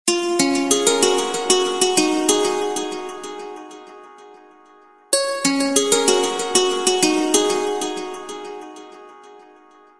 SMS alert tones guitar ringtone free download
Message Tones